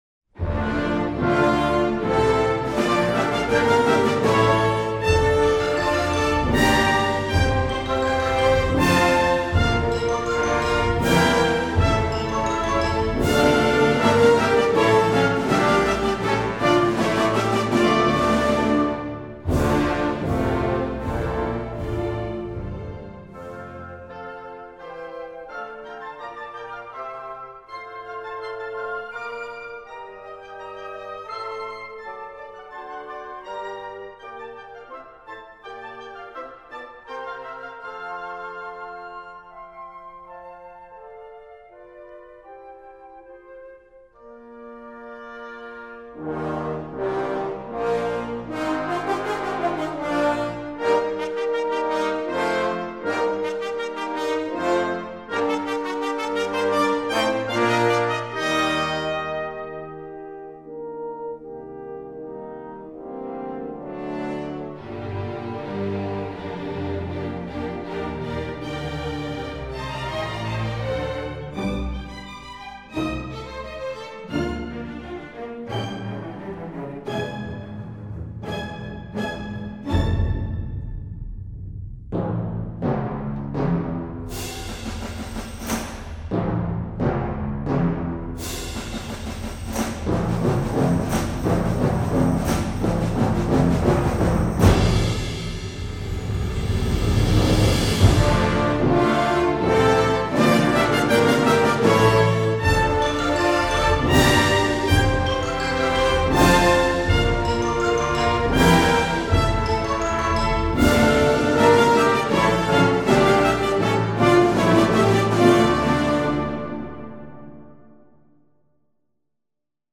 orquesta jovenes.mp3